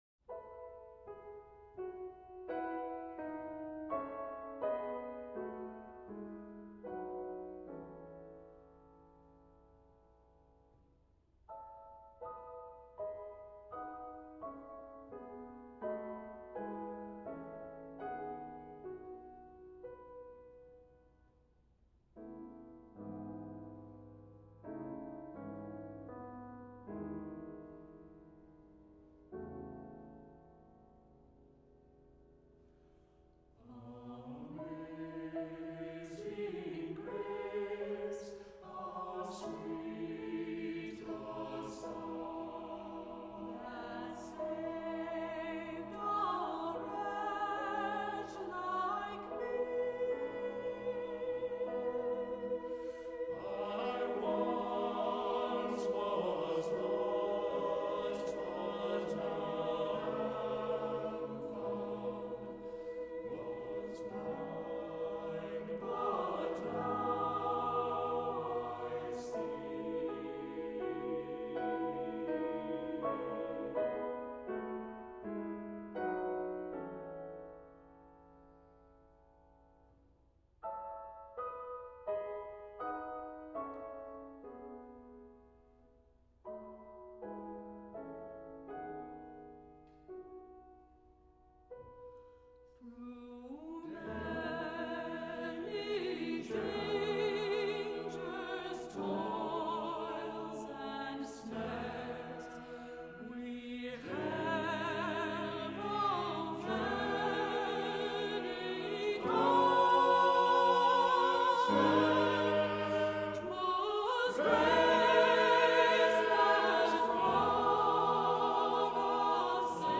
SATB a cappella Level